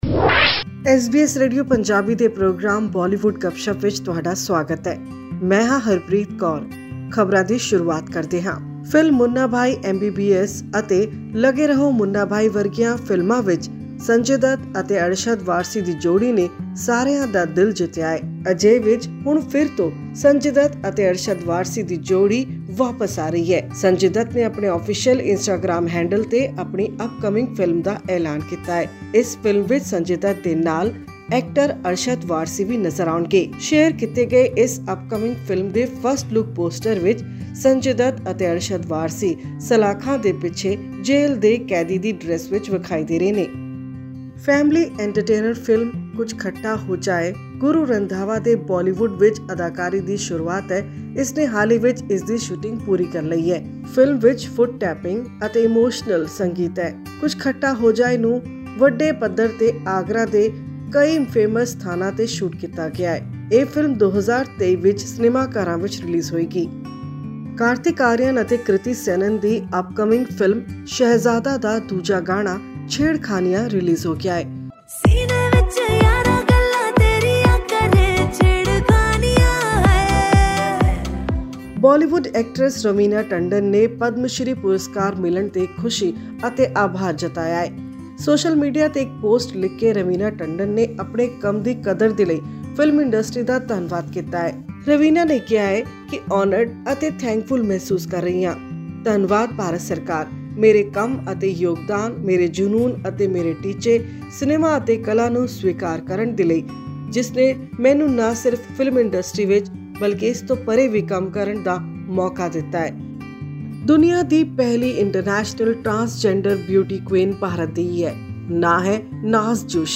This and more in our weekly news segment from the world of movies and music.